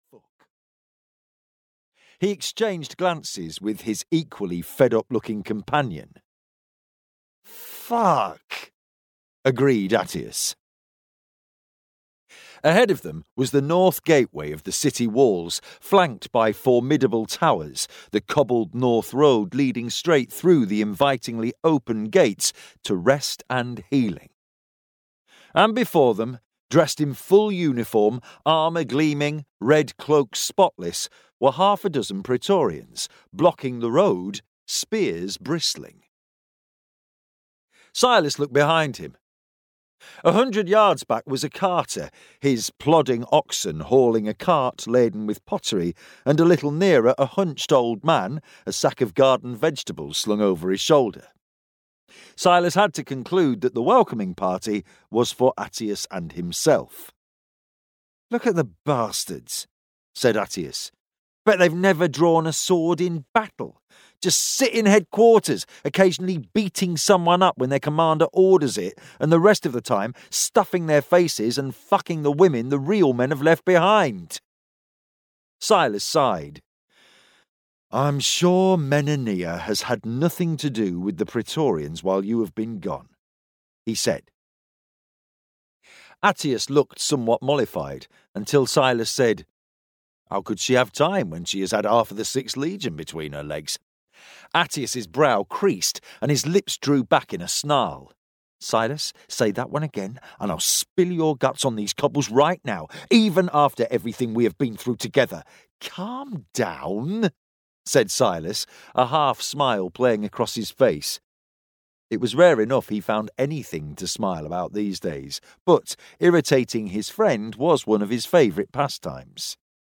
Emperor's Knife (EN) audiokniha
Ukázka z knihy